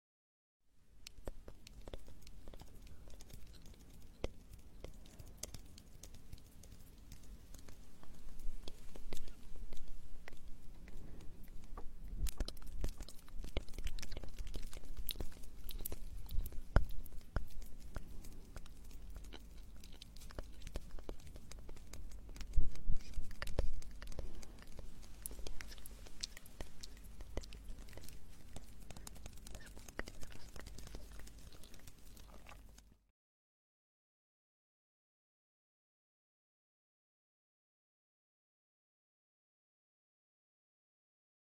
ASMR Inaudible Whispering & Sound Effects Free Download
ASMR Inaudible Whispering & Echo
#3dio